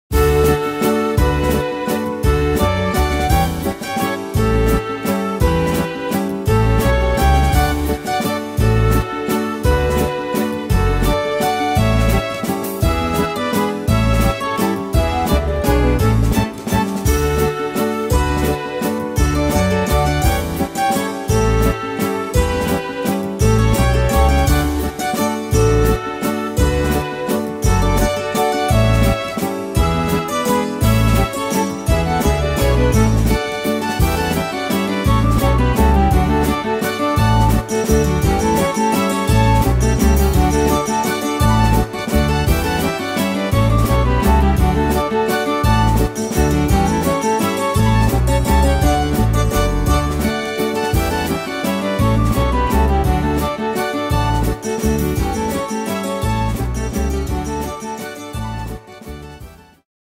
Tempo: 170 / Tonart: D-Dur